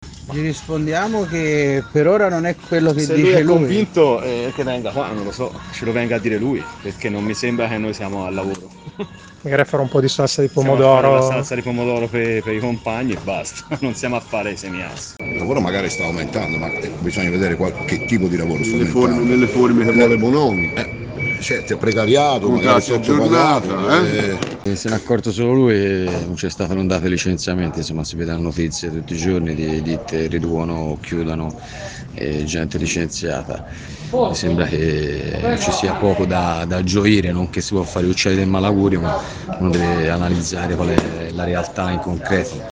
Gkn, in queste ore in presidio permanente in fabbrica per protestare contro i licenziamenti
montaggio-voci-GKN-raccolte-da-inviato-rispondono-a-Confindustria.mp3